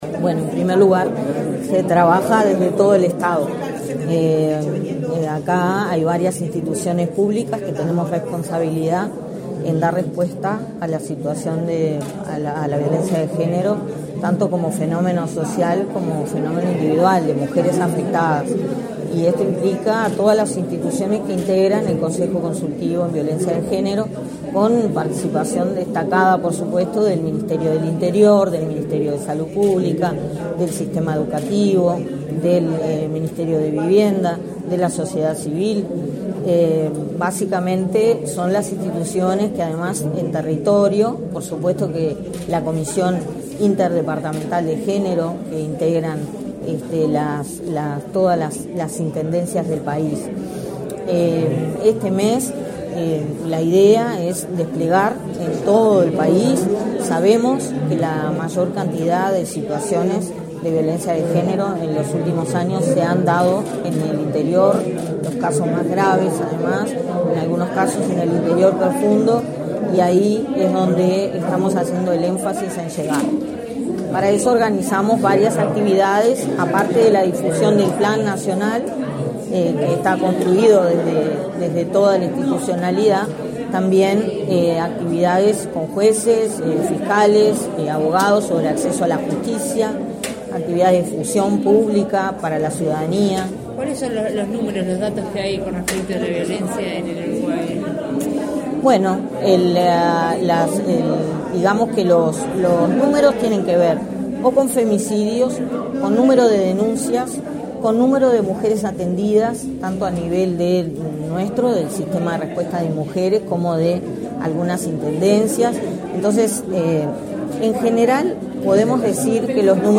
Declaraciones a la prensa de la directora del Inmujeres, Mónica Bottero
Botero prensa.mp3